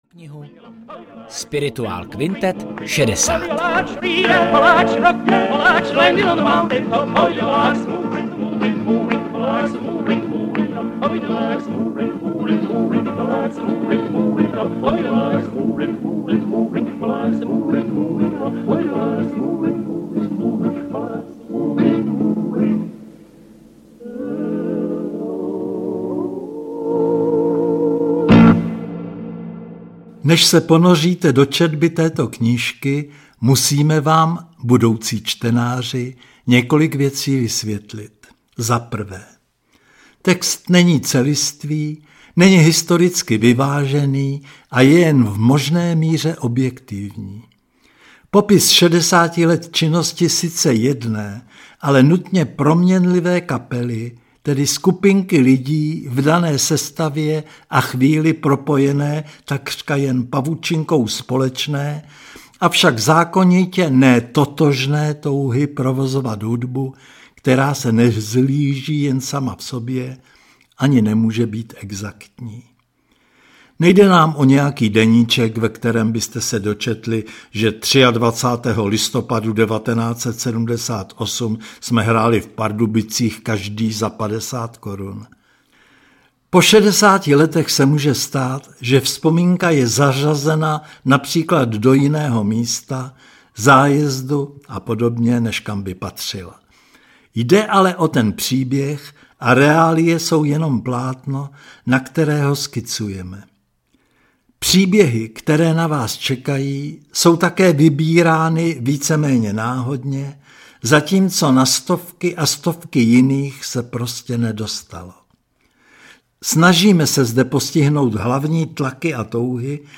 Spirituál kvintet audiokniha
Ukázka z knihy
• InterpretJiří Tichota, Různí interpreti